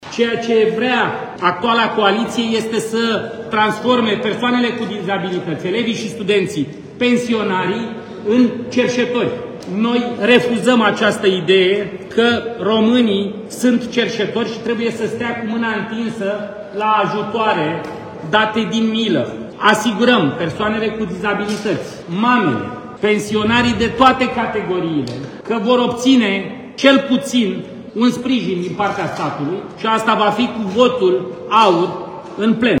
La rândul lui, liderul AUR, George Simion, a explicat că ajutoarele sociale nu pot fi oferite „din pix”, ci pot fi aplicate așa cum presupune legea, prin indexare: